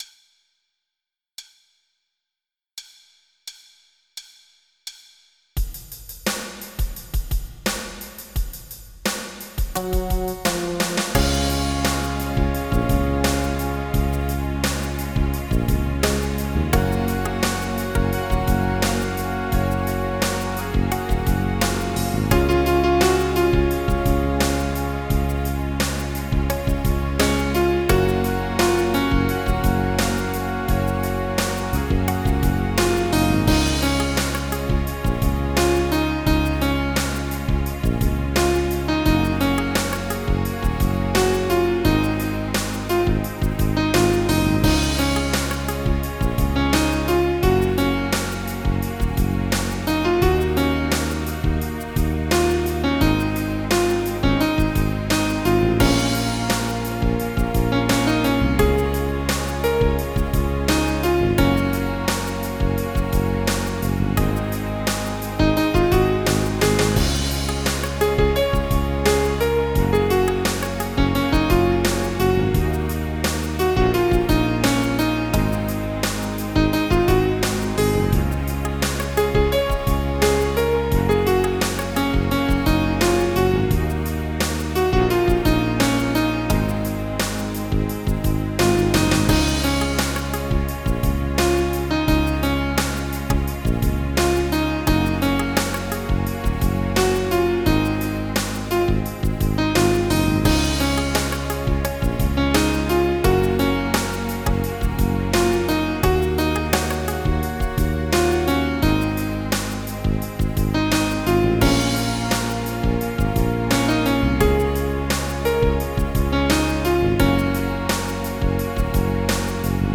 Pop
MIDI Music File